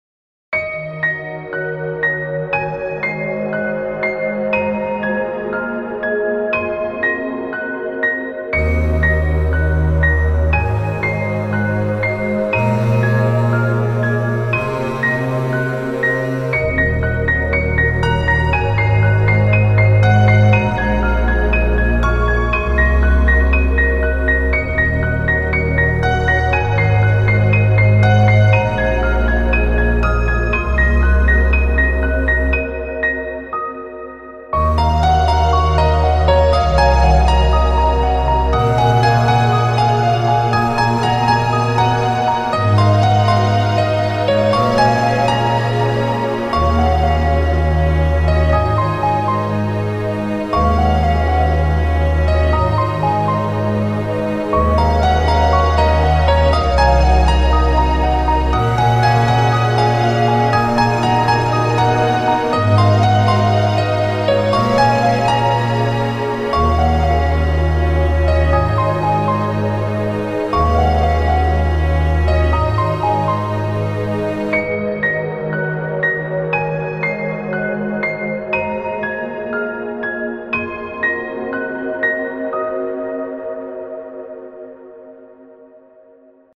そのため和楽器は使用せずピアノとストリングスを中心とした柔らかく幻想的な音作りになっています。
• テンポ：ゆったりとしたスローテンポ（約120 BPM）
• ピアノは柔らかく繊細に
• ストリングスは深みをもたせつつ低域をやさしく支える
ストリングスとパッドが調和しながらも、ピアノの“静けさ”と“希望”が語りかけるような構成です。
フリーBGM 癒し ピアノ 感動 七夕 祈り